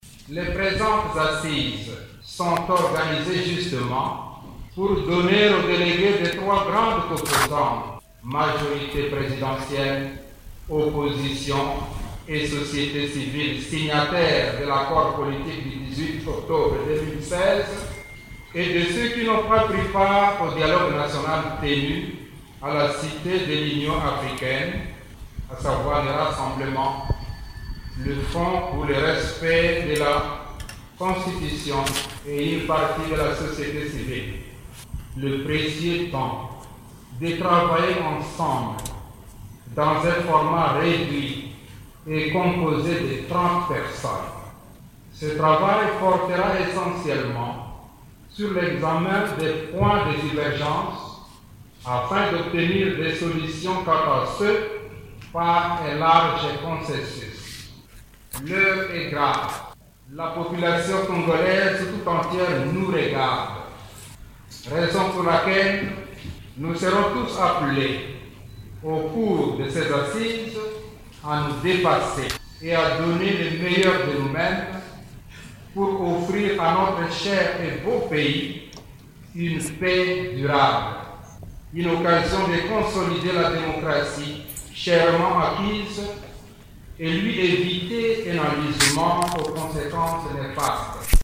Mgr Marcel Utembi à l’ouverture du dialogue en RDC/Top Congo